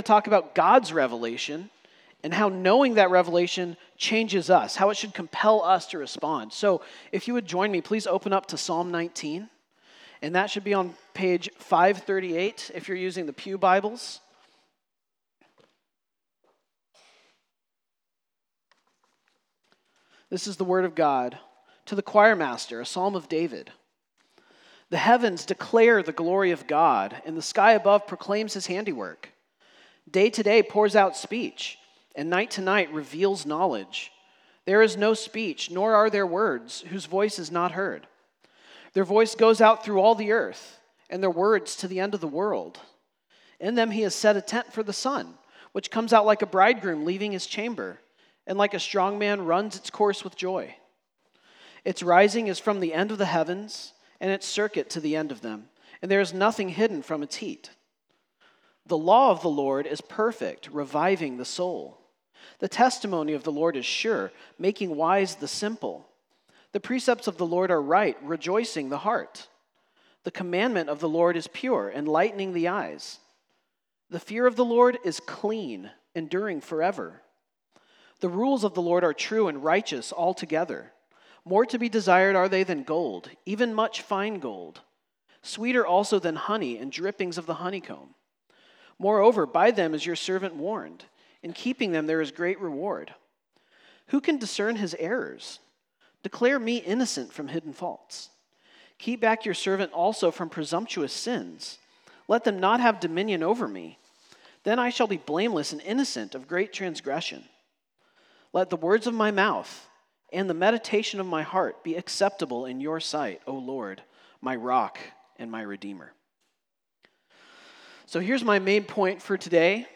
CCBC Sermons God is Speaking to Us (Psalm 19) Jul 13 2025 | 00:45:53 1x 00:00 / 00:45:53 Subscribe Share Apple Podcasts Spotify Overcast RSS Feed Share Link Embed